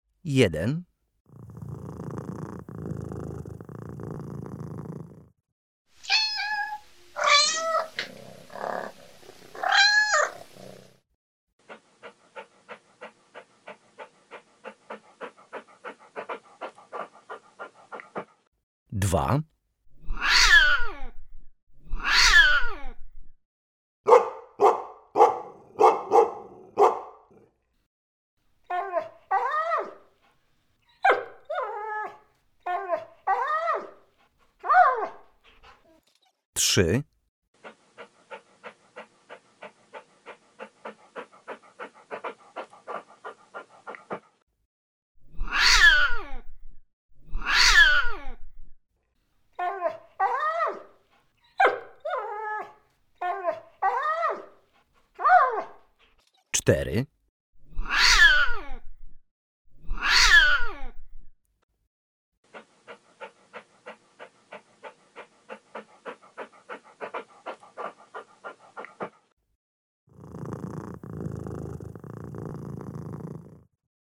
Karty aktywności - głosy zwierząt domowych - EDURANGA